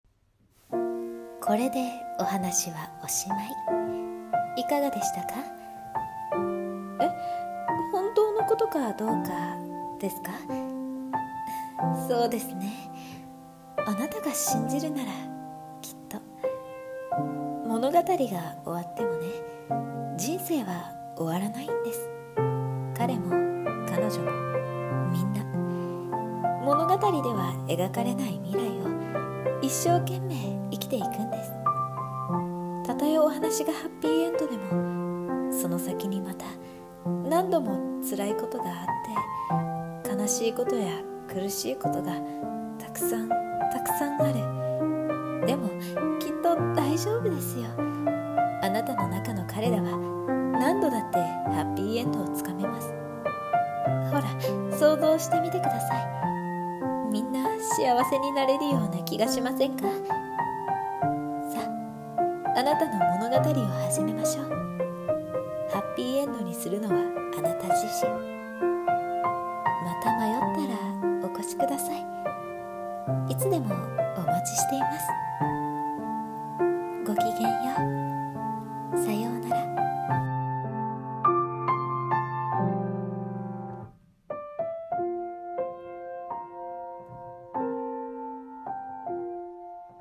朗読台本「エンドロールにて